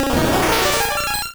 Cri de Paras dans Pokémon Rouge et Bleu.